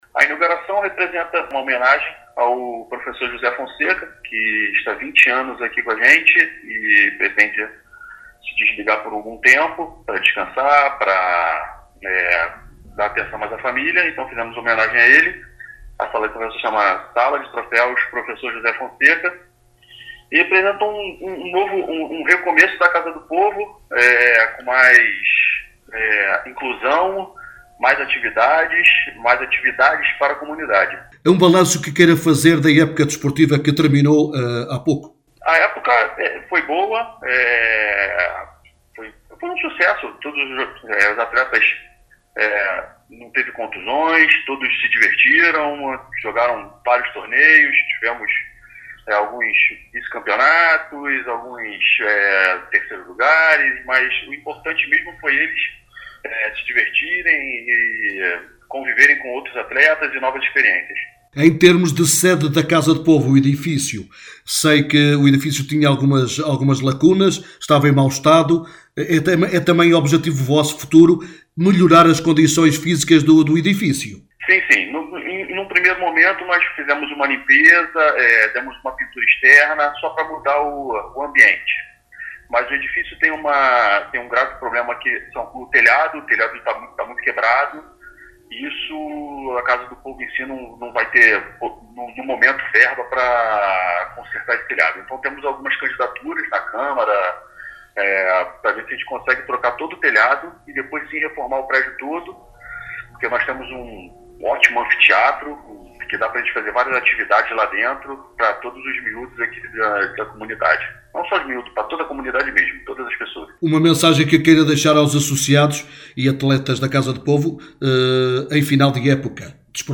em entrevista à Alive FM